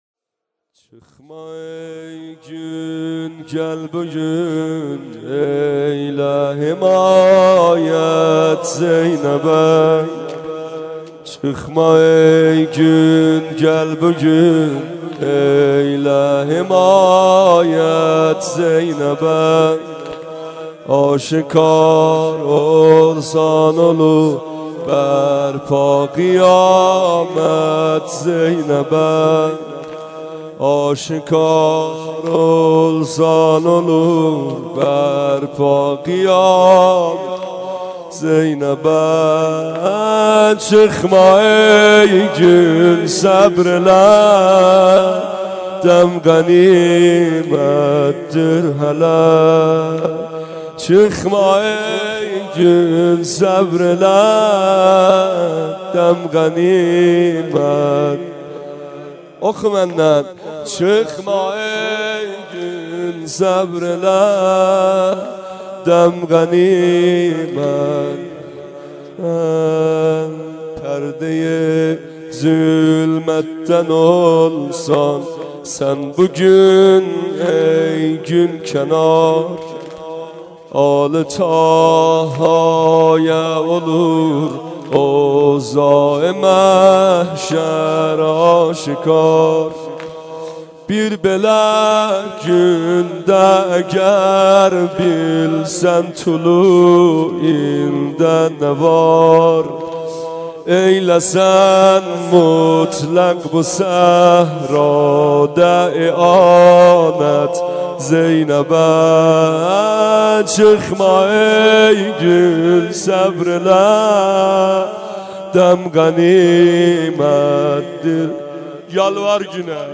سینه سنگین | چخما ای گون
سینه زنی سنگین مداحی جدید